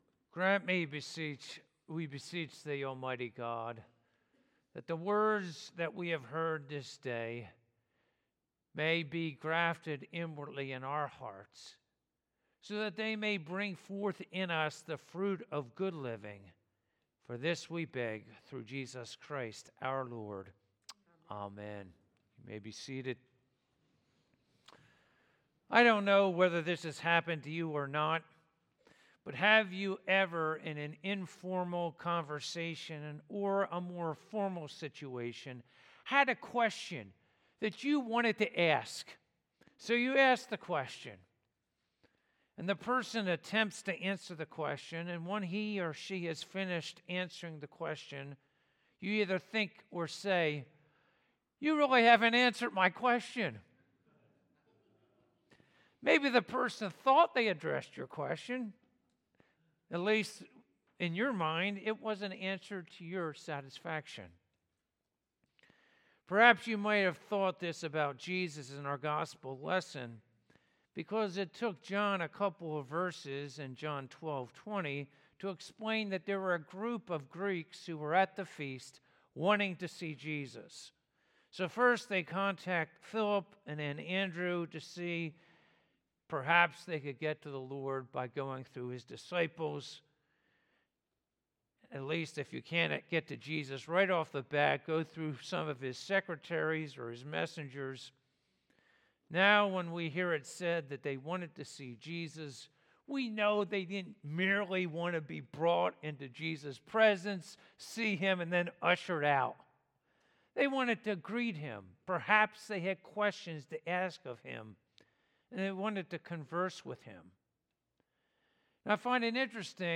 Passage: John 12:12-33 Service Type: Sunday Morning